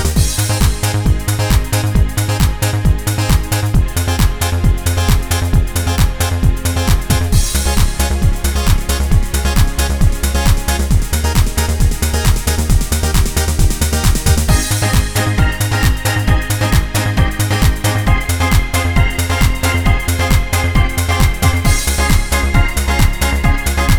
no Backing Vocals Dance 4:28 Buy £1.50